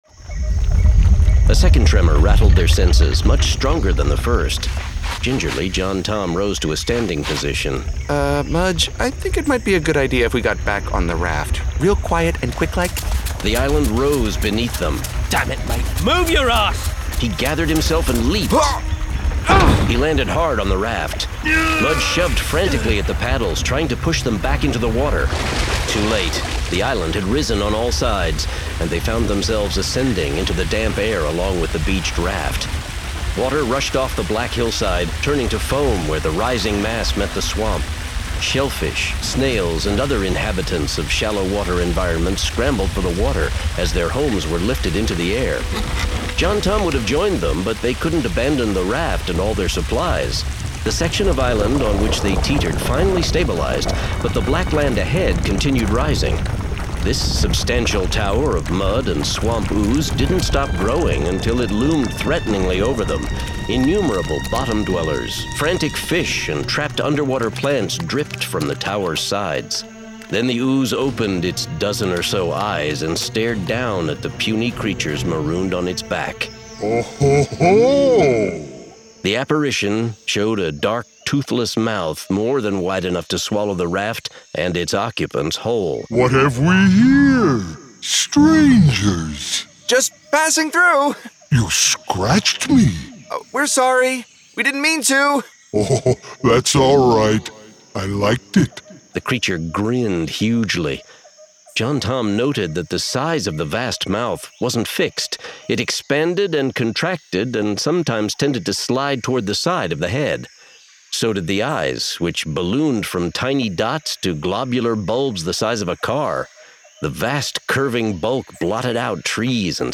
Spellsinger 4: The Moment of the Magician [Dramatized Adaptation]